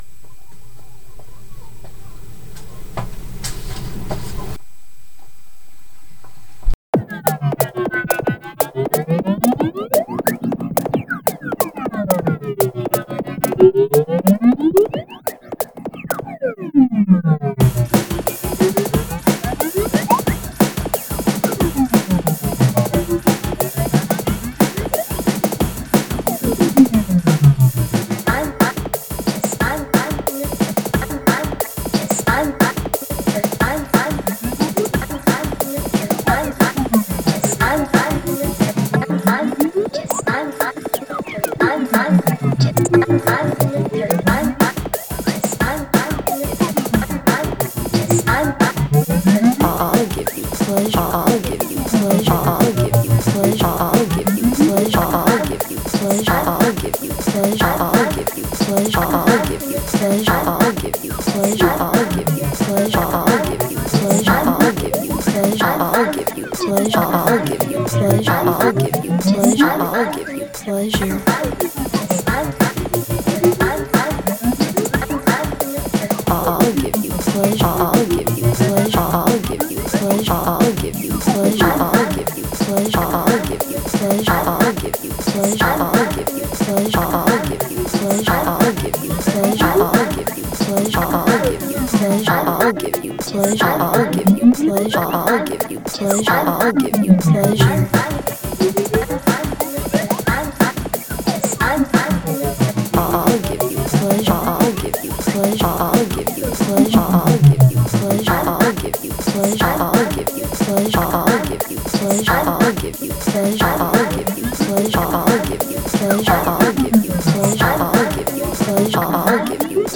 Genre: IDM.